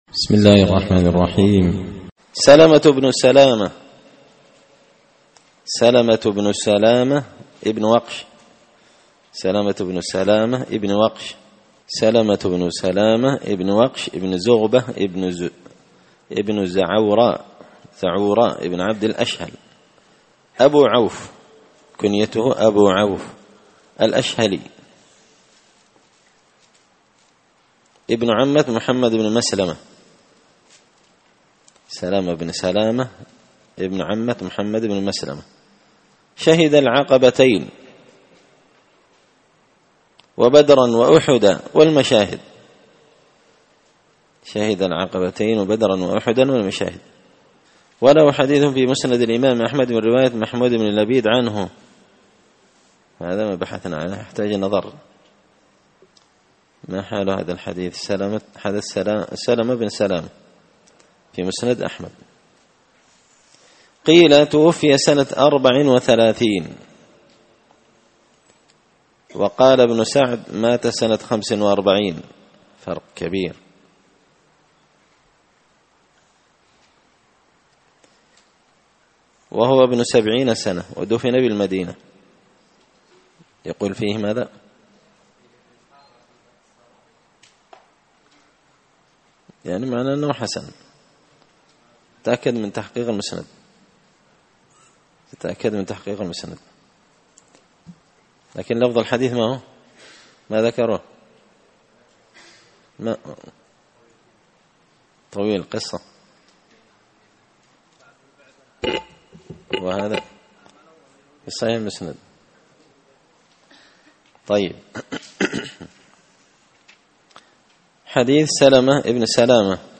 قراءة تراجم من تهذيب سير أعلام النبلاء
دار الحديث بمسجد الفرقان ـ قشن ـ المهرة ـ اليمن